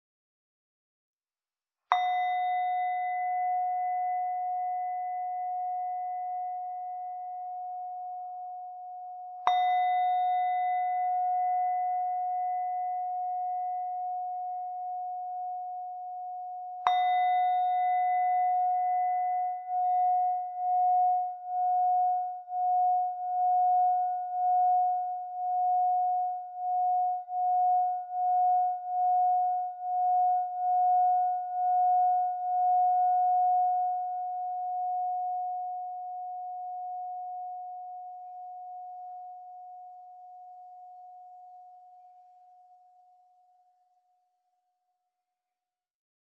The sounds produced are warm and relaxing, so singing bowls are frequently used worldwide in meditation practices and some yoga studios. Ornamental Series Singing Bowls are cast in India from brass alloy and finished with a hand-painted motif.